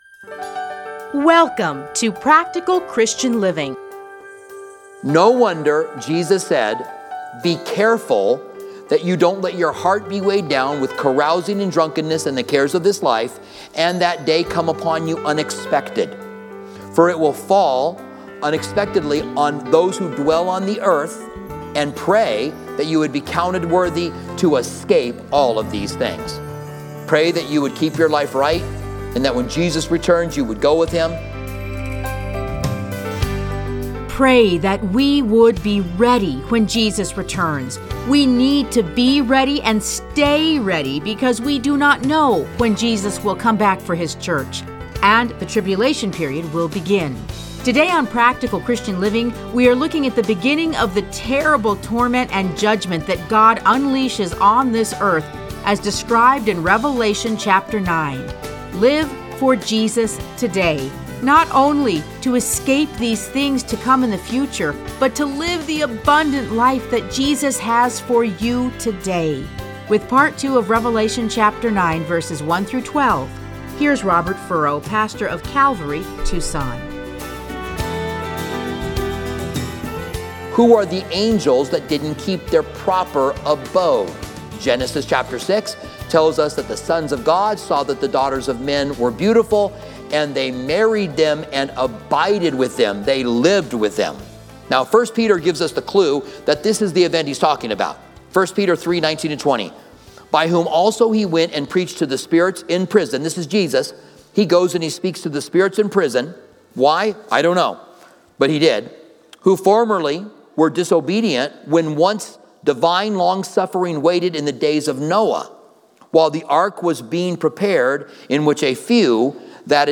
Listen to a teaching from Revelation 9:1-12.